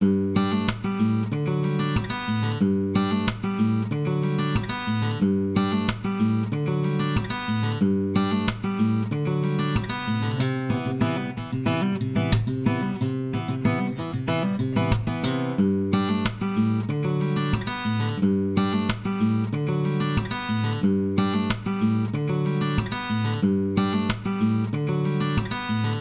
I have recorded a little bit of myself playing some guitar and would like for you all to critique the sound and overall quality of it. I was dinking around with some reverse reverb so that's the crappy sound when the riff breaks.